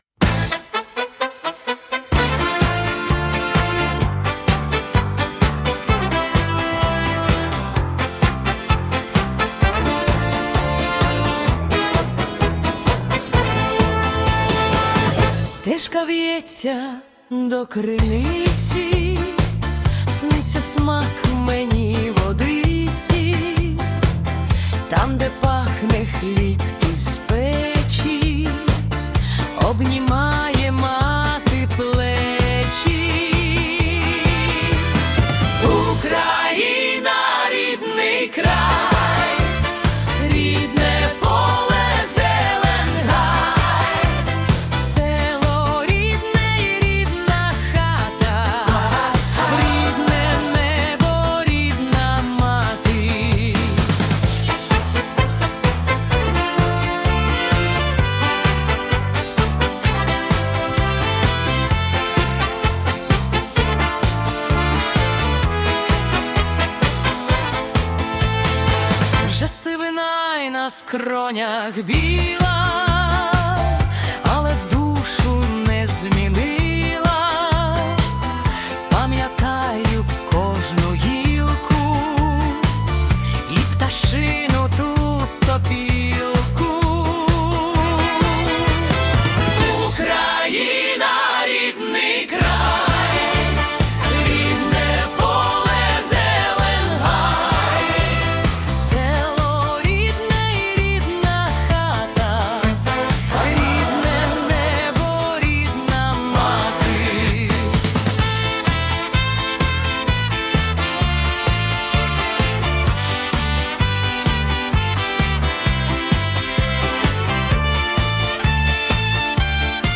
Українське ВІА / Ретро 70-х